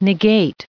added pronounciation and merriam webster audio
1512_negate.ogg